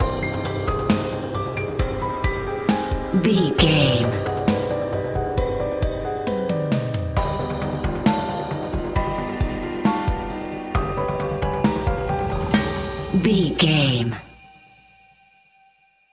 Ionian/Major
eerie
ominous